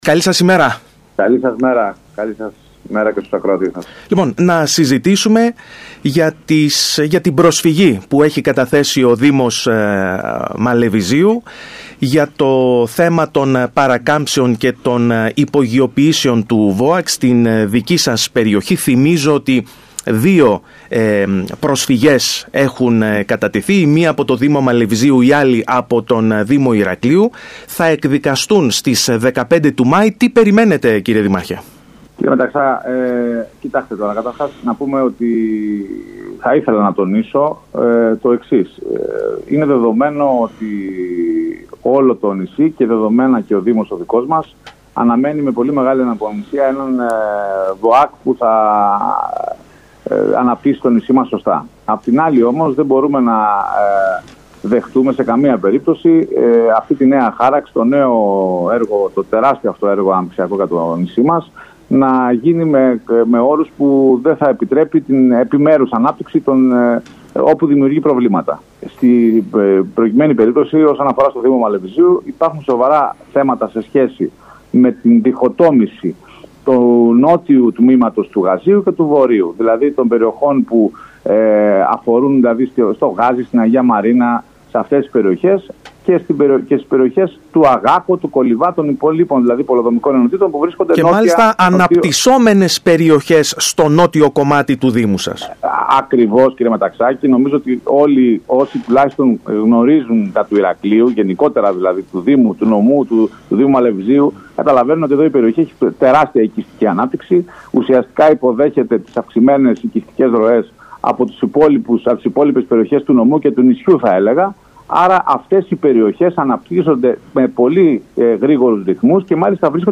“Υπάρχει επικοινωνία με το υπουργείο Υποδομών και διάθεση να γίνουν διορθώσεις”, δήλωσε στο ραδιόφωνο του Σκάι Κρήτης 92,1 ο δήμαρχος Μαλεβιζίου Μενέλαος Μποκέας πρόσθεσε ωστόσο πως η υπόθεση θα φτάσει μέχρι το ΣτΕ.
Ακούστε εδώ όσα είπε ο κ.Μποκέας στον ΣΚΑΙ Κρήτης 92,1: